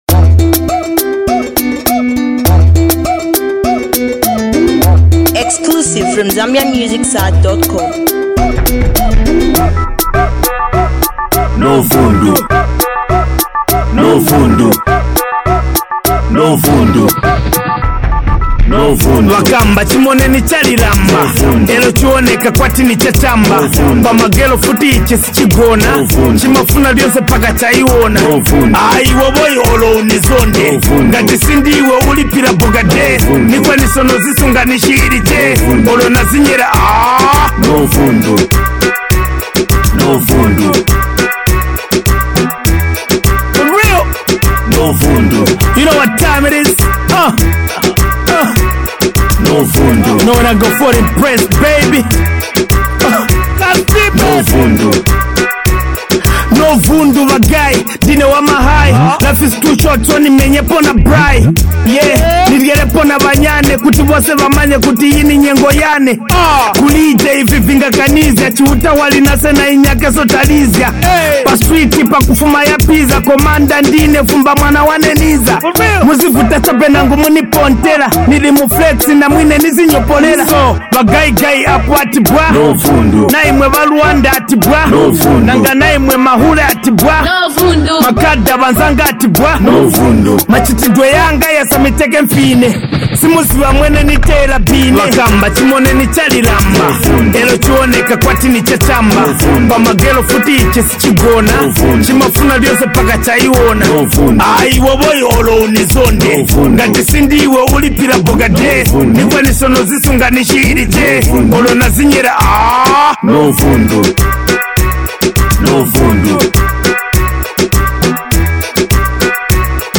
Here is a street anthem
it’s a nice song with a great vibe